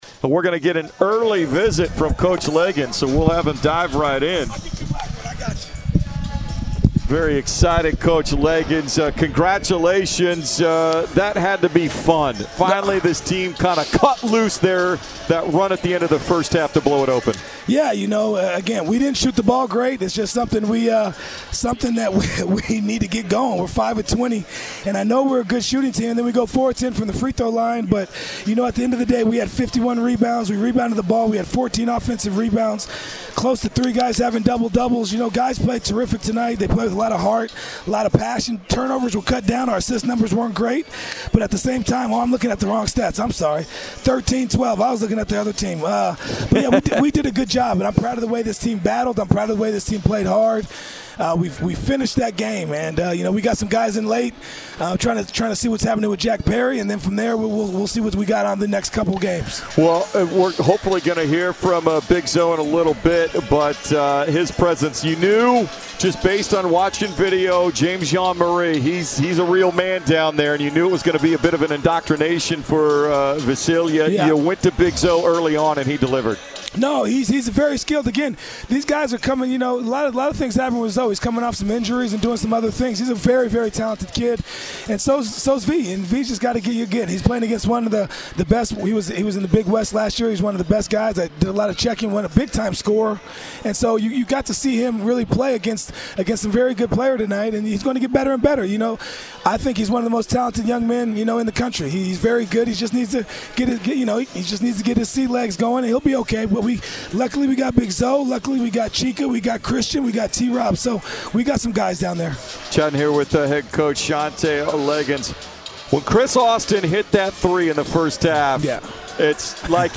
Post-Game Radio Interview